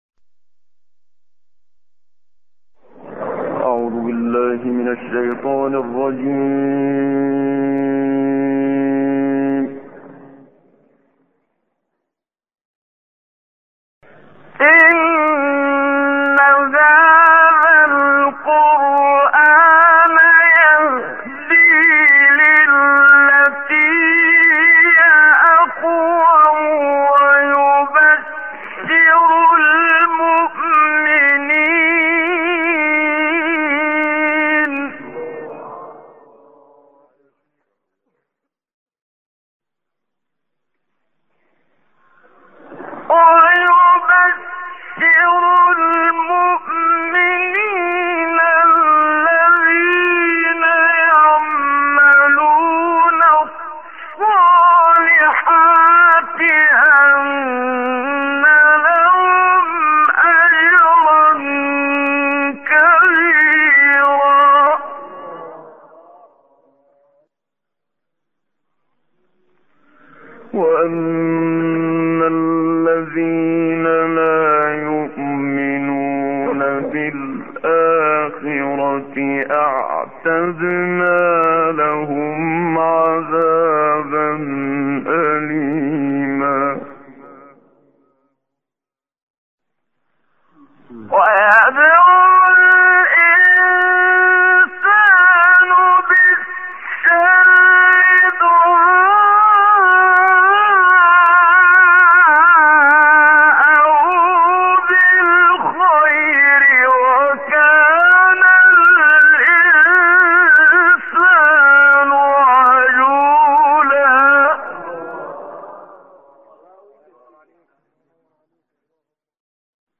دانلود آیه 9 سوره مبارکه اسراء با صوت استاد منشاوی